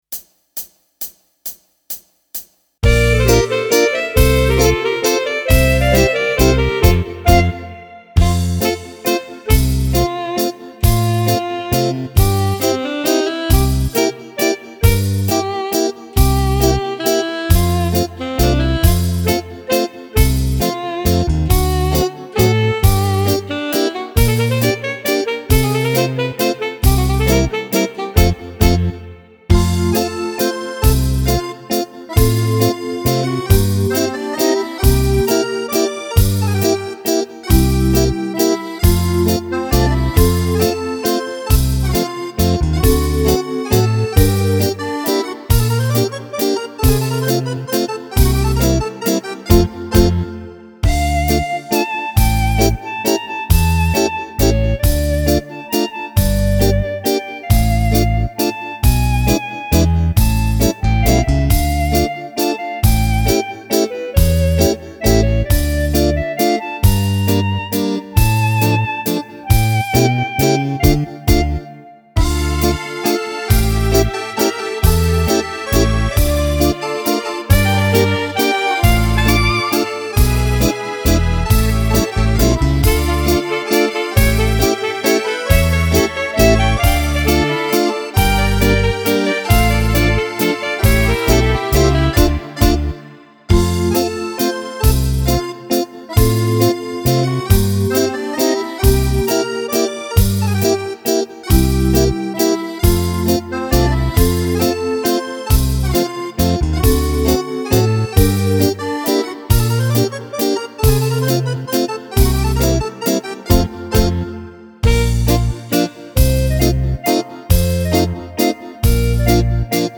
(solo base)